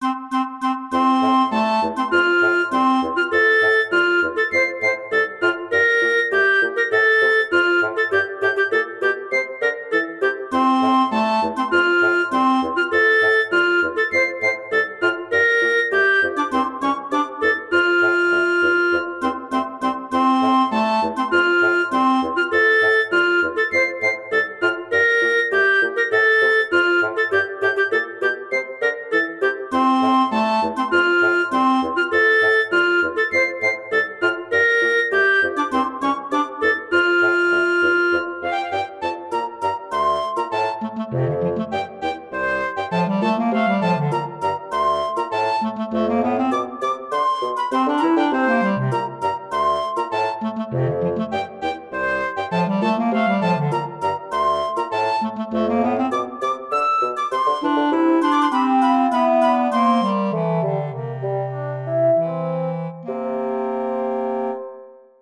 木管トリオが奏でる平和で穏やかな日常。
ループ